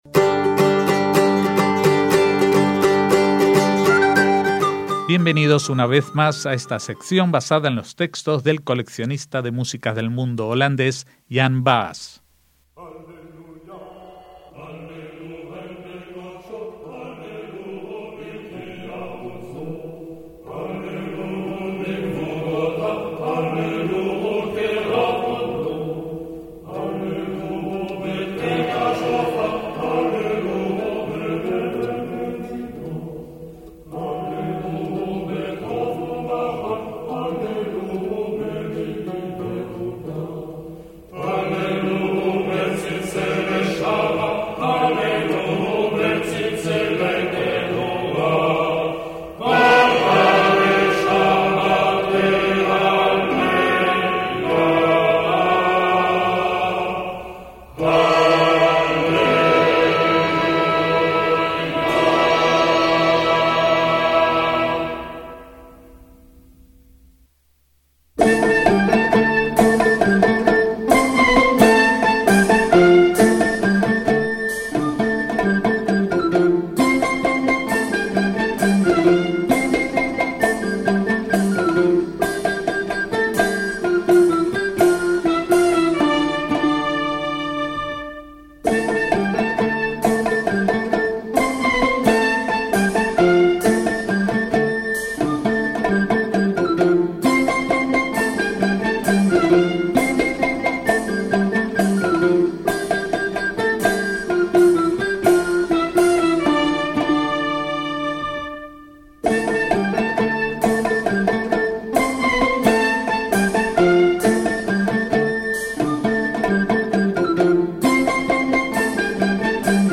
En este caso se podrán escuchar algunas reconstrucciones de cómo se supone sonaba en tiempos bíblicos la música del Templo de Jerusalén.